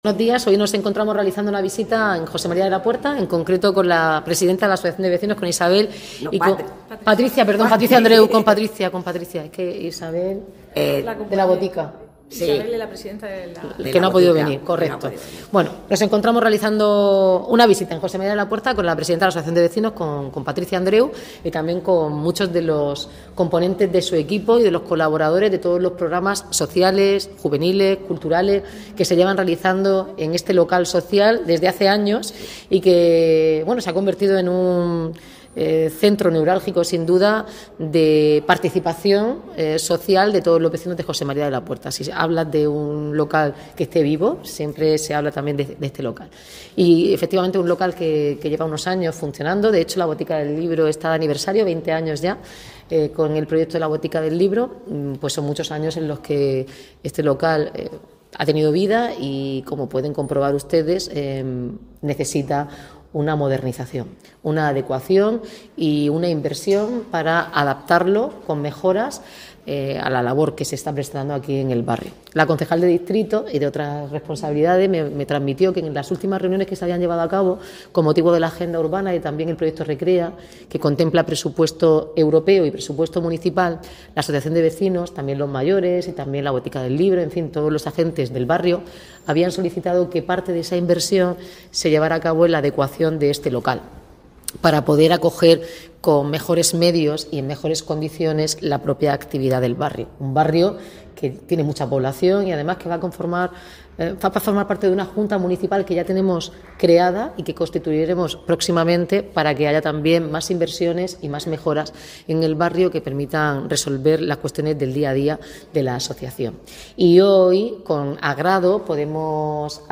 La alcaldesa, Noelia Arroyo, anuncia durante una visita al barrio la adecuación y modernización del espacio, además de nuevas inversiones en asfaltado, seguridad escolar y una pista polideportiva exterior